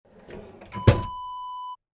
Kitchen Drawer Close Wav Sound Effect #6
Description: The sound of a kitchen drawer being closed
Properties: 48.000 kHz 16-bit Stereo
A beep sound is embedded in the audio preview file but it is not present in the high resolution downloadable wav file.
drawer-kitchen-close-preview-6.mp3